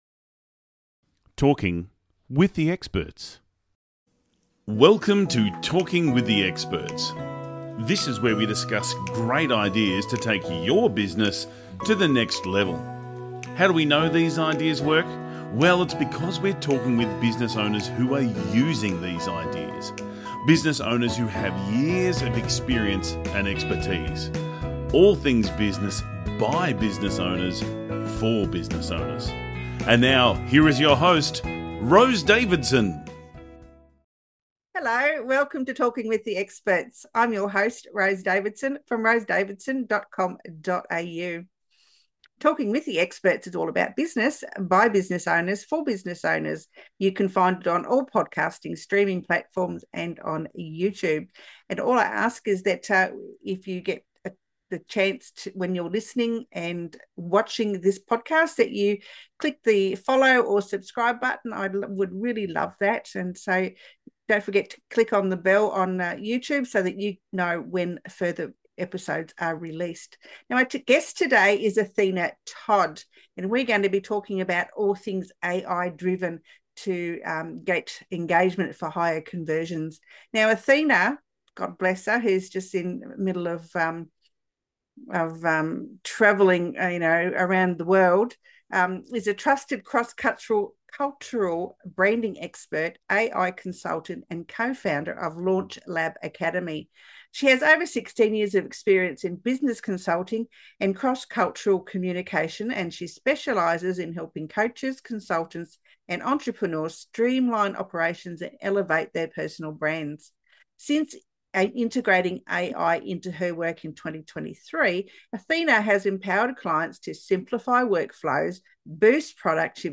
A significant portion of the conversation focuses on the efficiency gains achieved through AI integration.